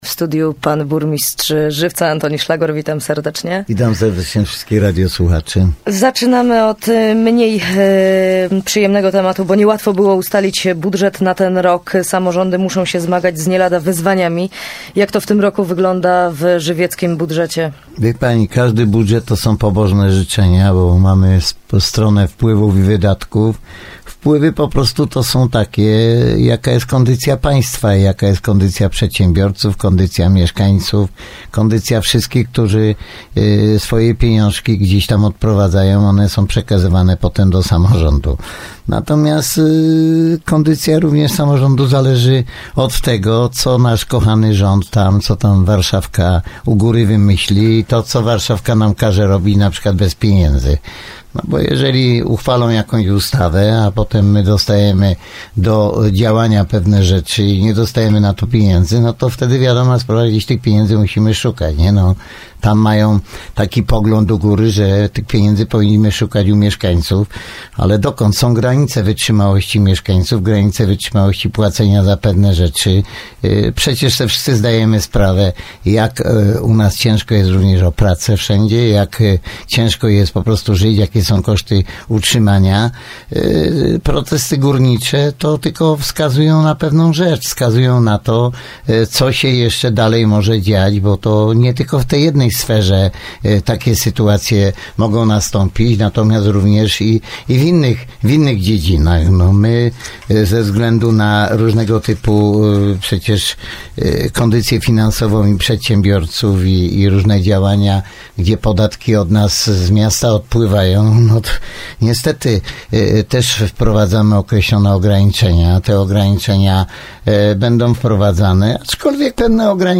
22:46:36 1 + Burmistrz miasta # zywiec w Radiu Bielsko. http